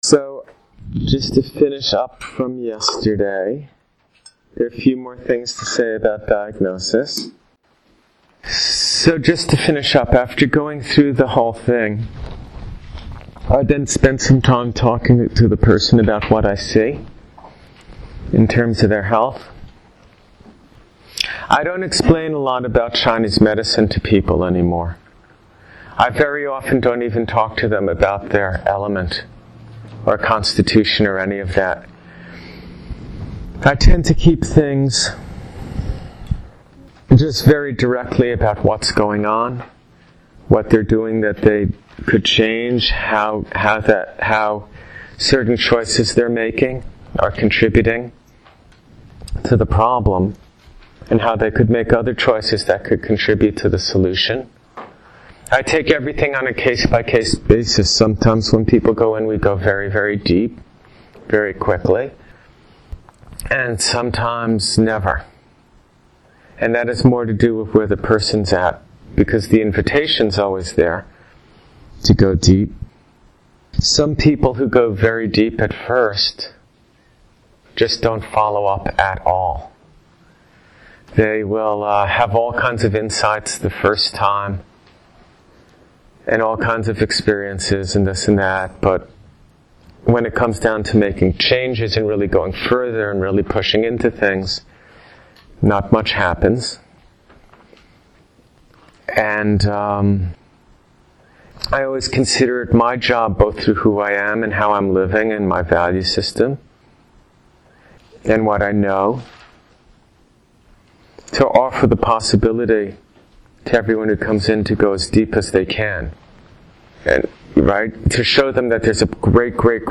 This lecture was given to my students on 11.16.08